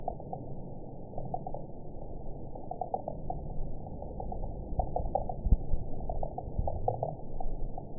event 921992 date 12/24/24 time 20:24:13 GMT (5 months, 3 weeks ago) score 5.98 location TSS-AB03 detected by nrw target species NRW annotations +NRW Spectrogram: Frequency (kHz) vs. Time (s) audio not available .wav